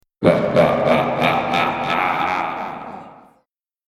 scary-laugh-123862.mp3